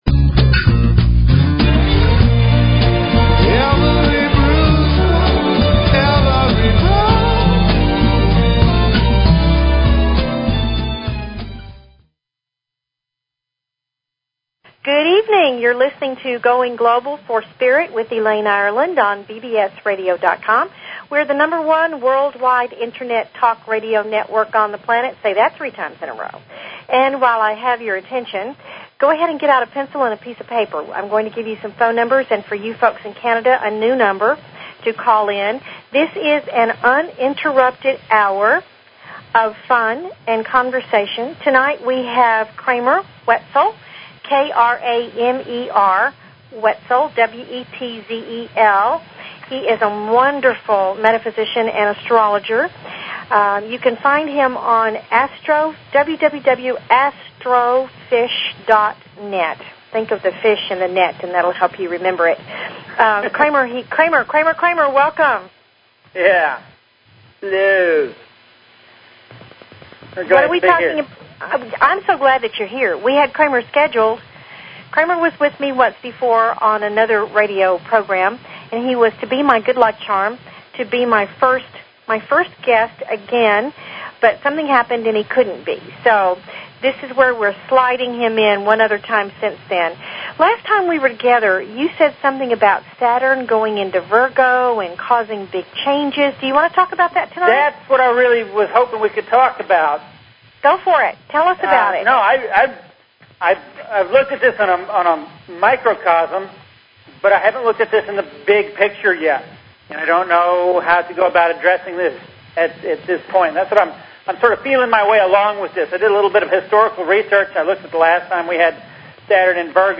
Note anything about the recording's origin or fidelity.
CALL IN WITH ANY ASTROLOGY QUESTIONS.